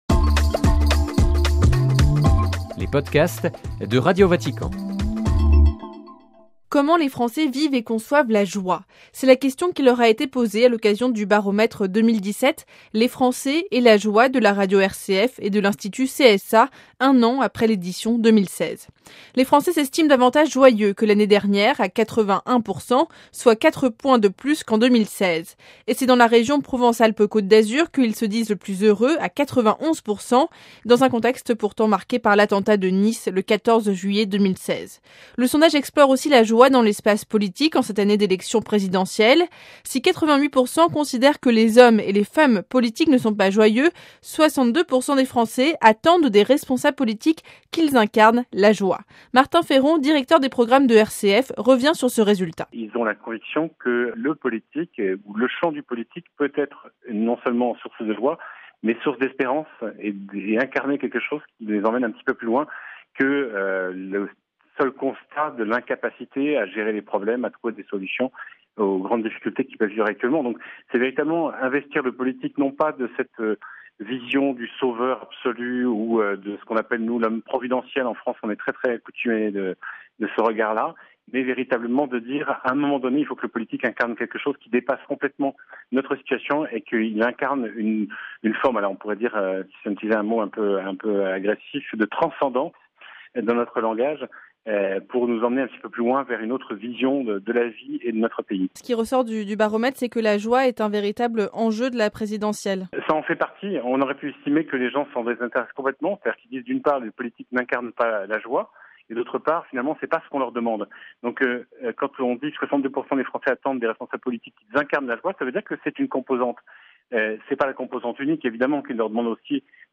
(RV) Entretien - Comment les Français vivent et conçoivent la joie ?